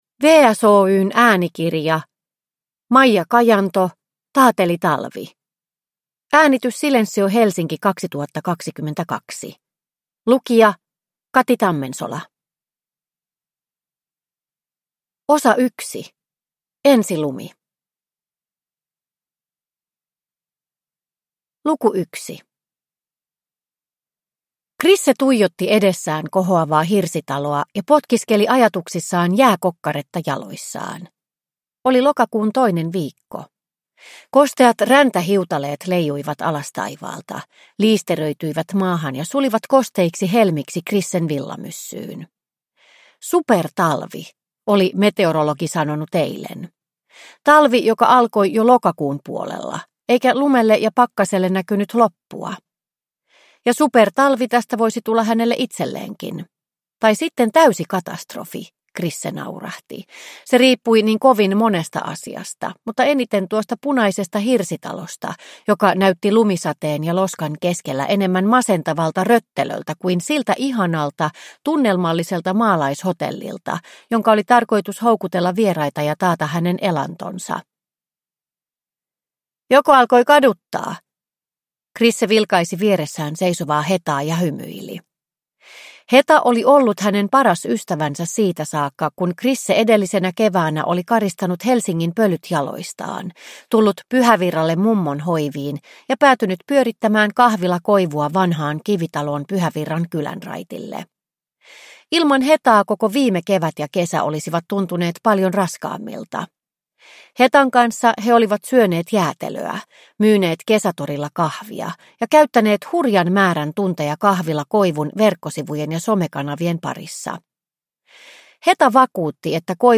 Taatelitalvi – Ljudbok – Laddas ner